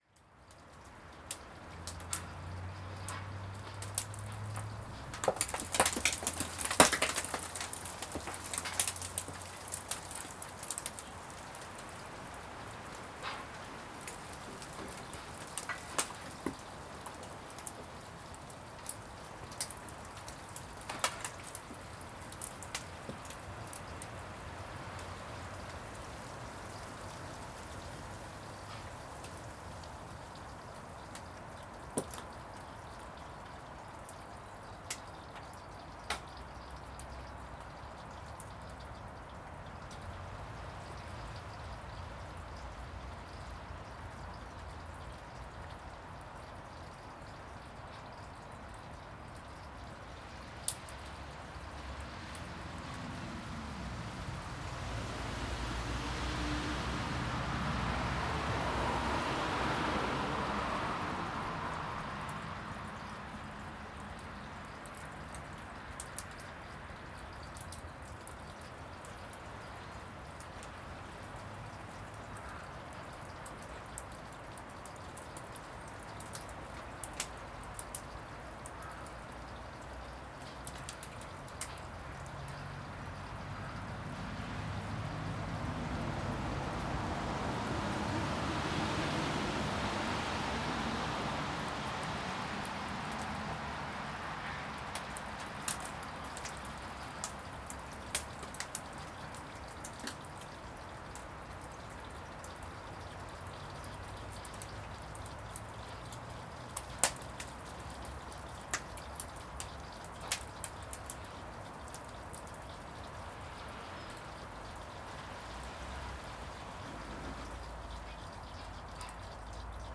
Instead of birds and nice spring vibes, we get iced rain and dripping eaves.
Alt...Stereo recording with a constant sloshing like dripping on the right from the eaves. And hard smacks above, when ice falls on the plastic roof of our porch. There are cars driving by and an occasional train gives its toot in to the mix.